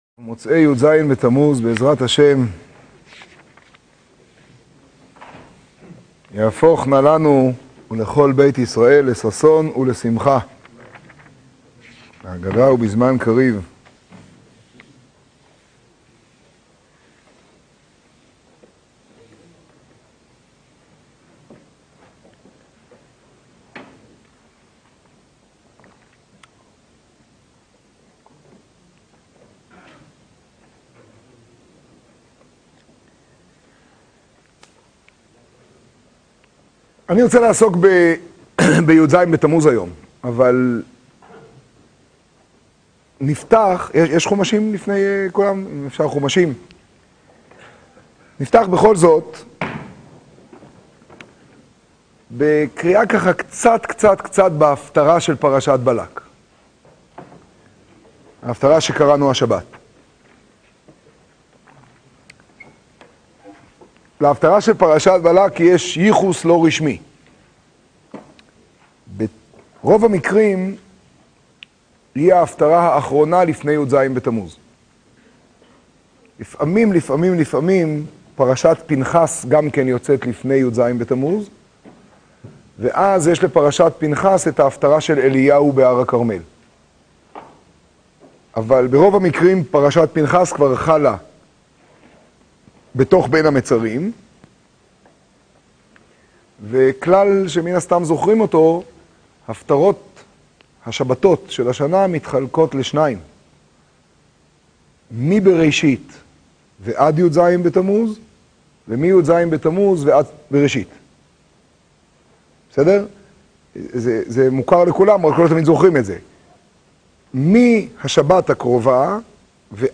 השיעור בירושלים פרשת פנחס להאזנה / הורדה